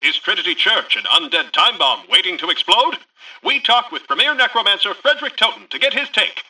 Newscaster_headline_53.mp3